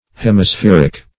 Meaning of hemispheric. hemispheric synonyms, pronunciation, spelling and more from Free Dictionary.
Hemispheric \Hem`i*spher"ic\